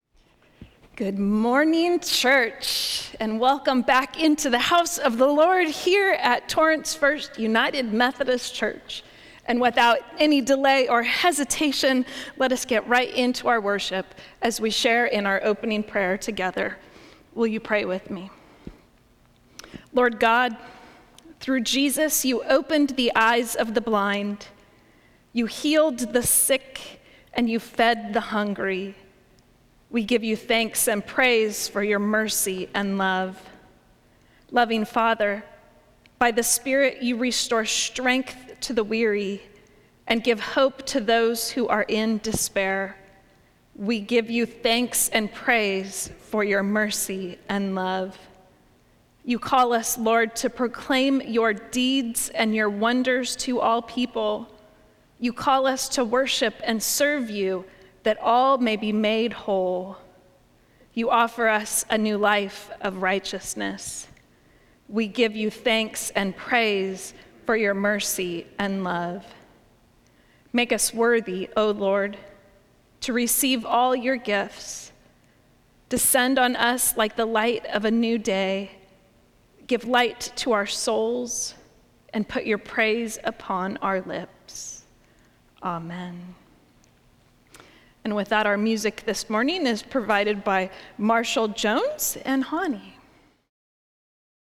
Service of Worship
Welcome and Opening Prayer